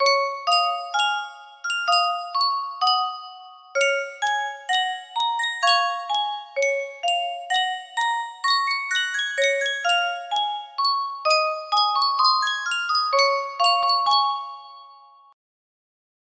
Yunsheng Music Box - めだかの学校 5456 music box melody
Full range 60